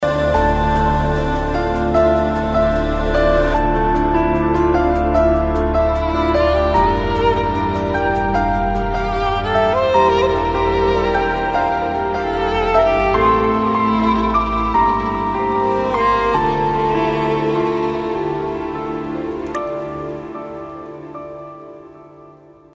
رینگتون رمانتیک و بی کلام